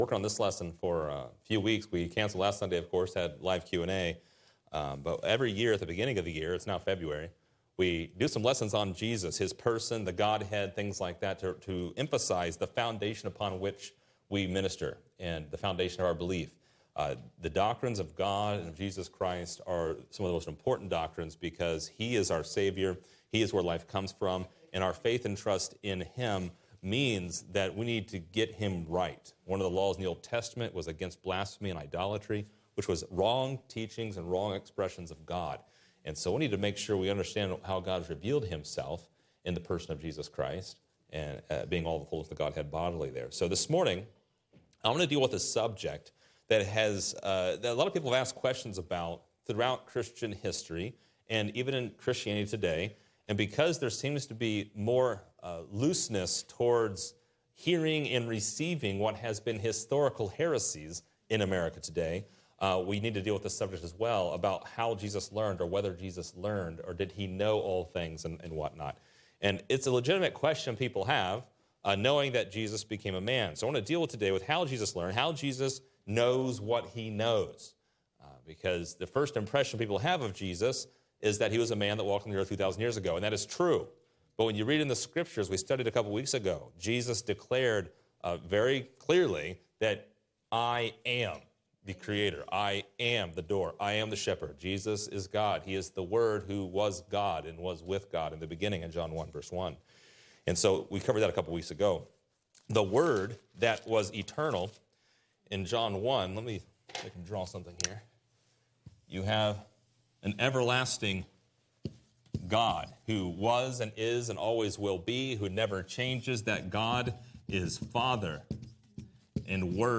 Did He know all things because of His deity, or did He have to learn how to be God because of His humanity? This lesson attempts to answer those questions from the Bible rightly divided.